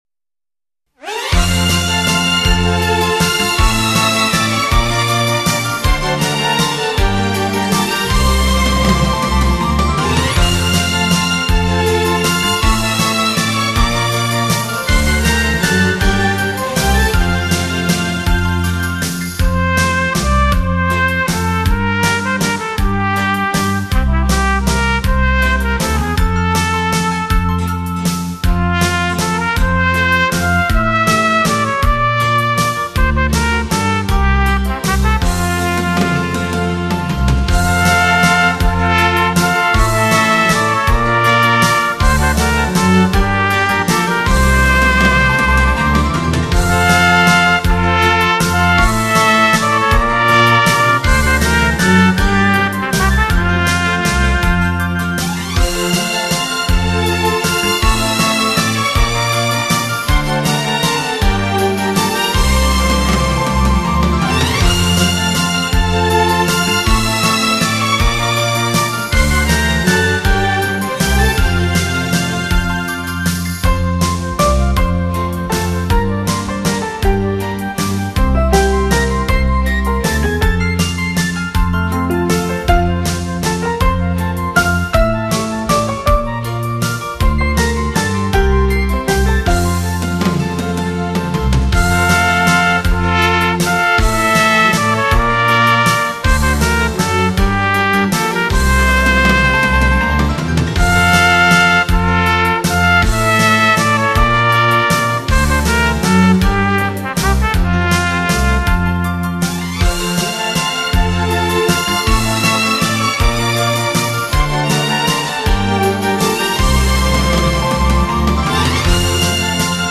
轻歌妙韵度良宵
快三